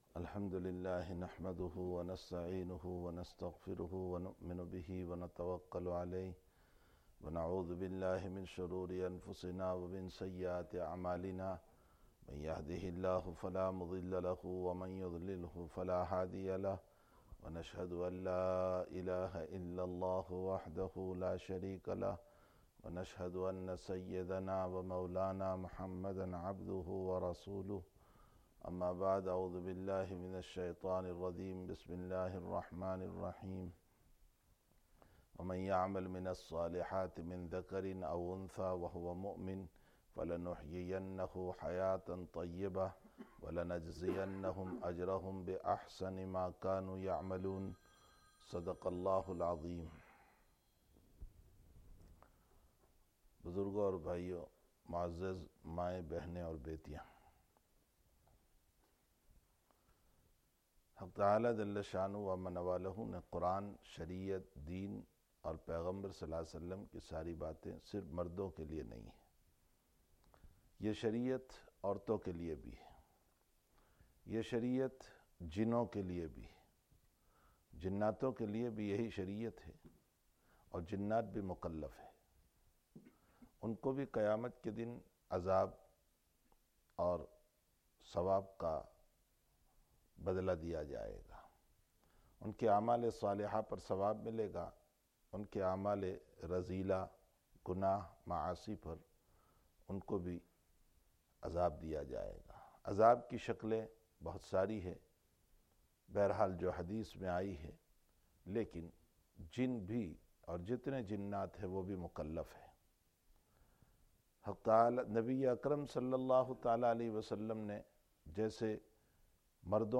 Masjid Quba Sisters Bayaan